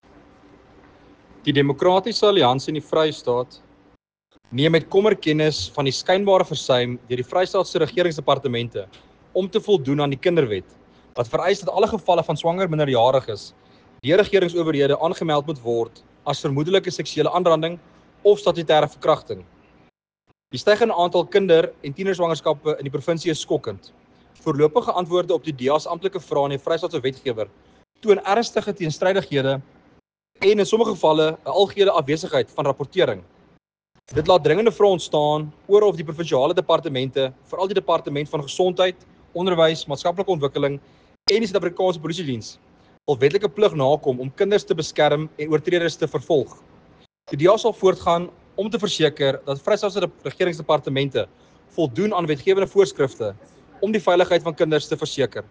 Afrikaans soundbite by Werner Pretorius MPL.